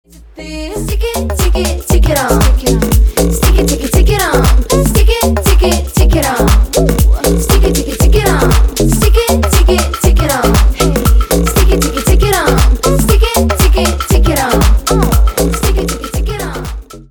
поп
ритмичные
dance